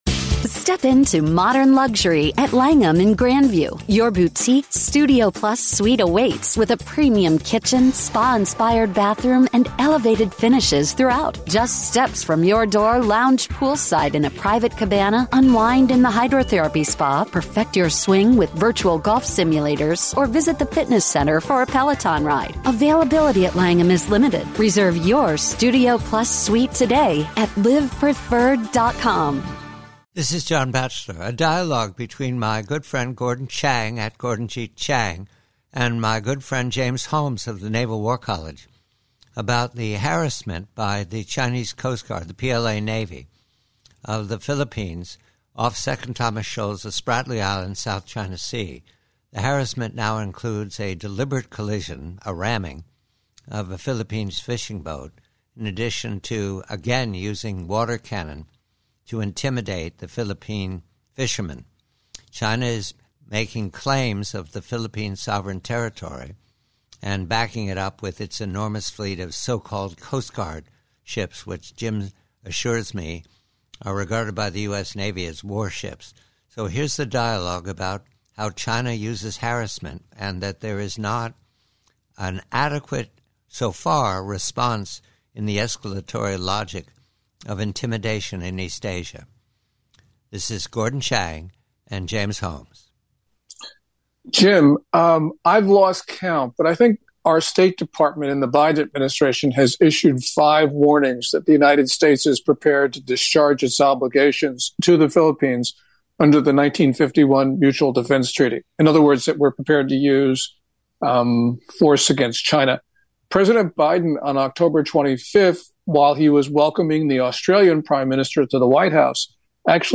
From a longer dialogue